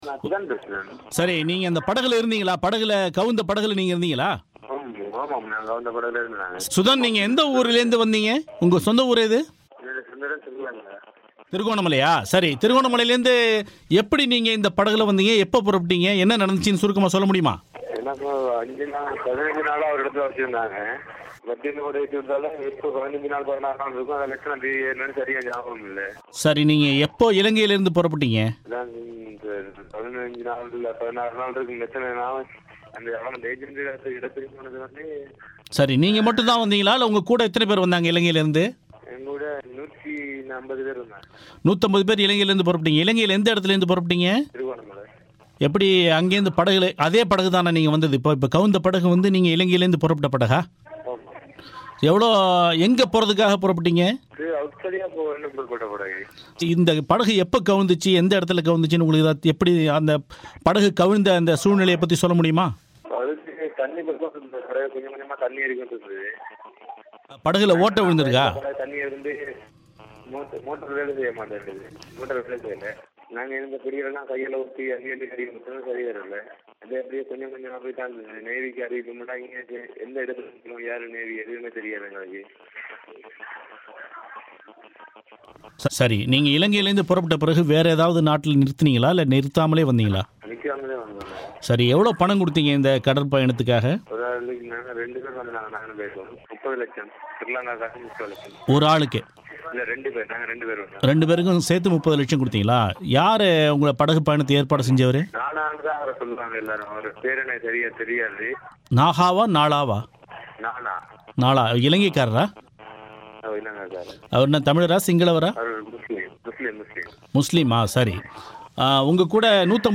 படகு கவிழ்ந்ததில் உயிர் தப்பிய இலங்கையர் பேட்டி